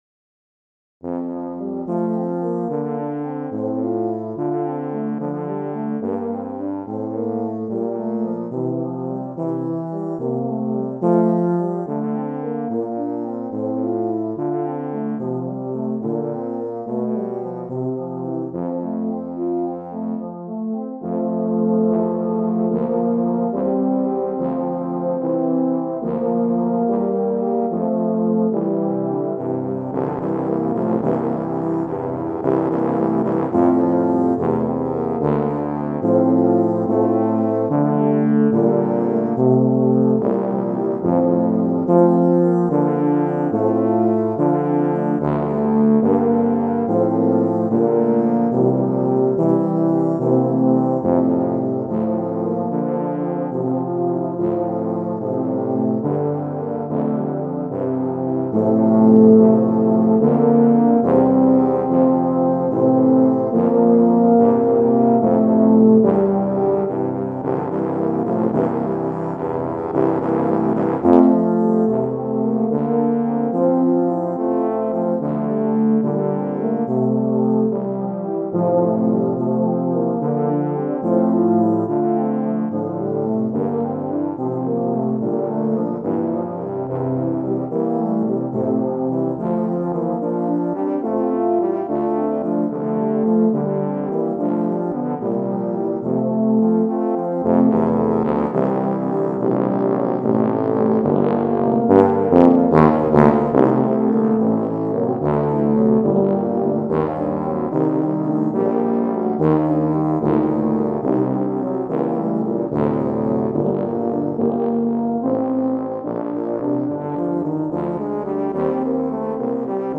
Voicing: Tuba Ensemble